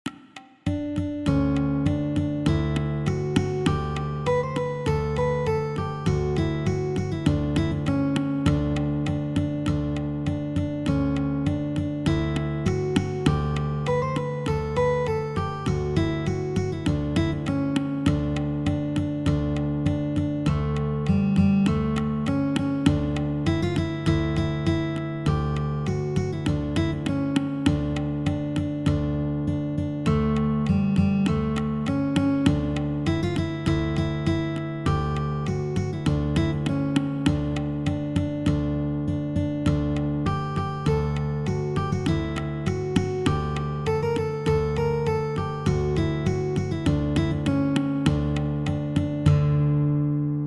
The arrangements use both standard and drop-D tunings.